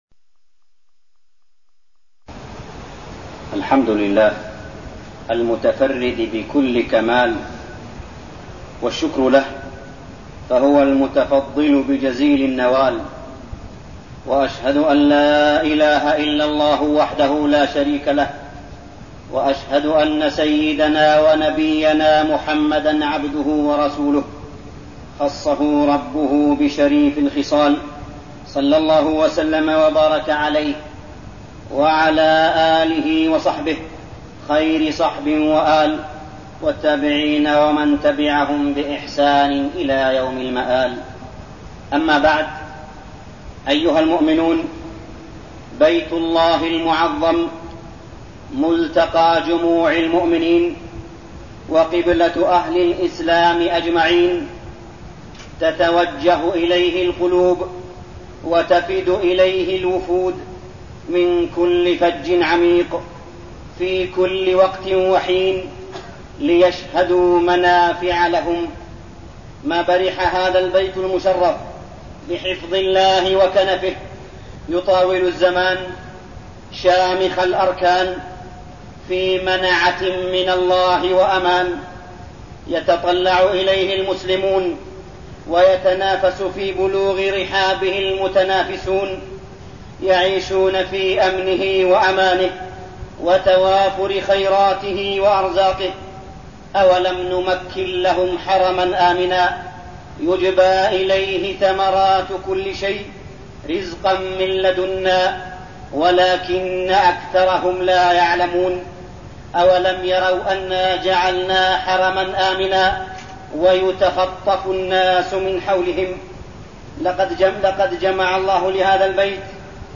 تاريخ النشر ٧ ربيع الأول ١٤١٠ هـ المكان: المسجد الحرام الشيخ: معالي الشيخ أ.د. صالح بن عبدالله بن حميد معالي الشيخ أ.د. صالح بن عبدالله بن حميد سيرة النبي صلى الله عليه وسلم